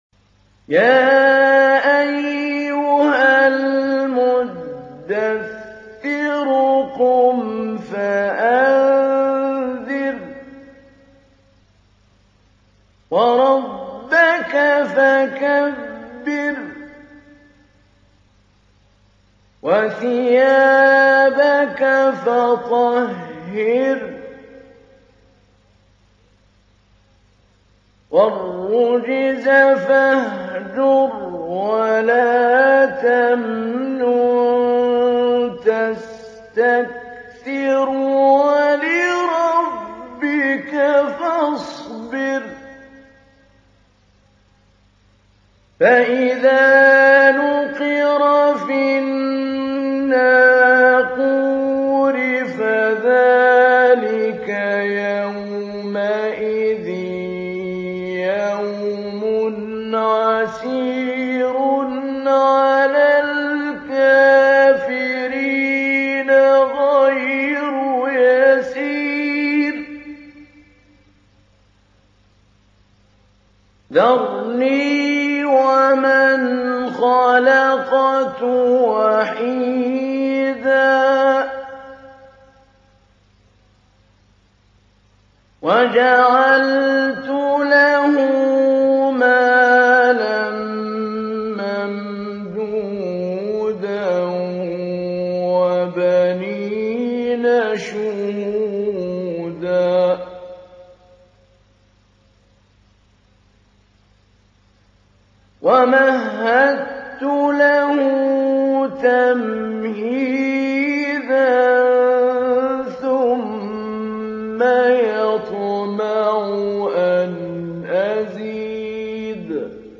تحميل : 74. سورة المدثر / القارئ محمود علي البنا / القرآن الكريم / موقع يا حسين